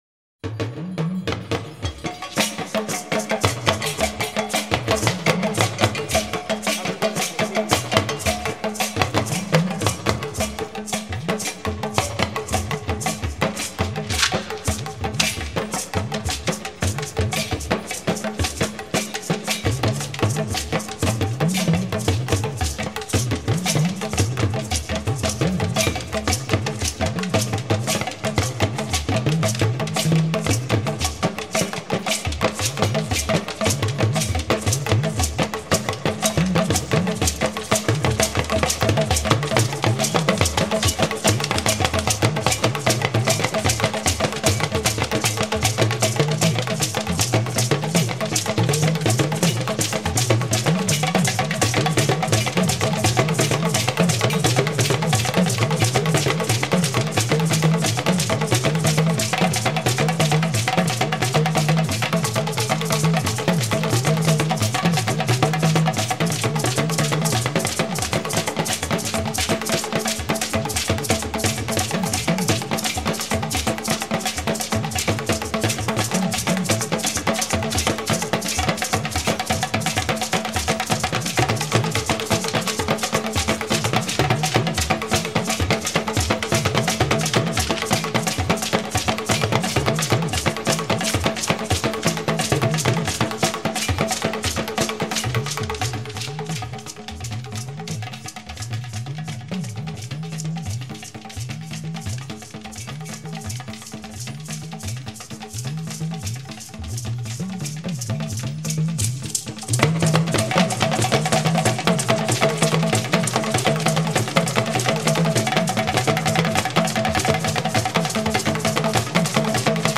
最道地的西非传统鼓乐
如unden、iyalu（大鼓）、kanango（中鼓）、omele或qanqan（小鼓），
酣畅淋漓地展演出高难度的鼓技，偶尔出现的人声吟唱搭配不同的音色层层相叠，
形成复杂的节奏形态。